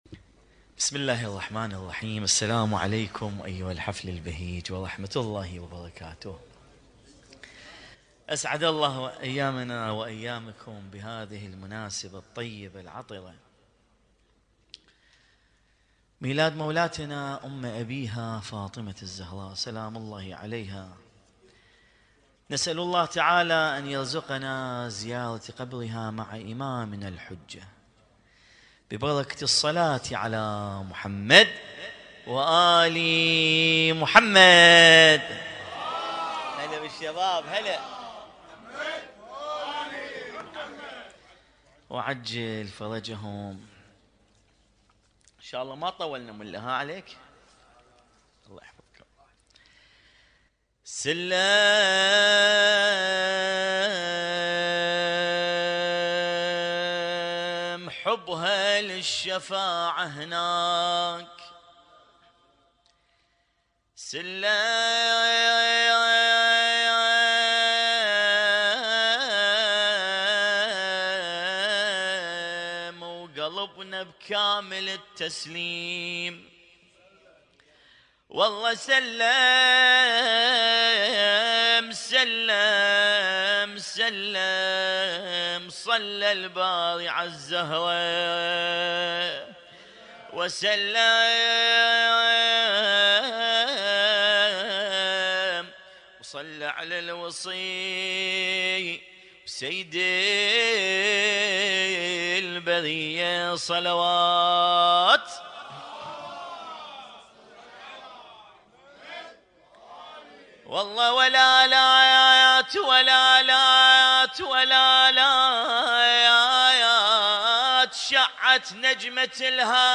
Husainyt Alnoor Rumaithiya Kuwait
القارئ: الرادود